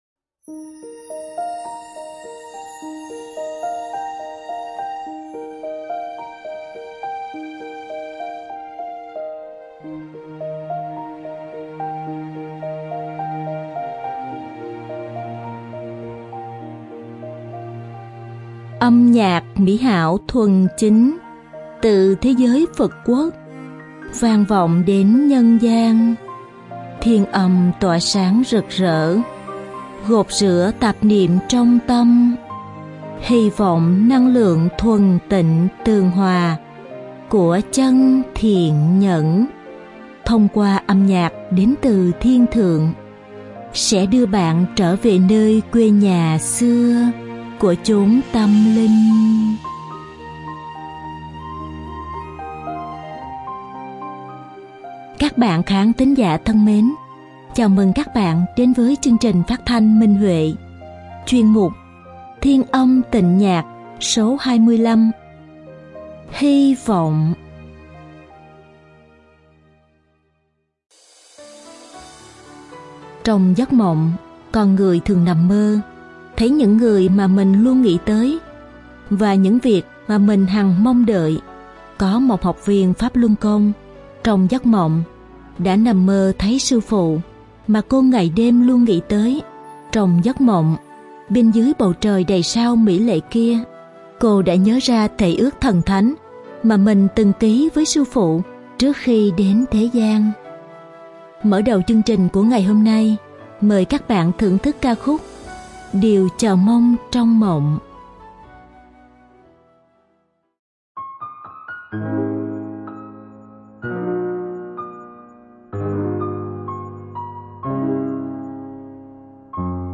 Đơn ca nữ
Hợp xướng
Sáo dài, đàn hạc cầm